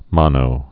(mŏnō)